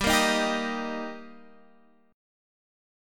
Gm#5 chord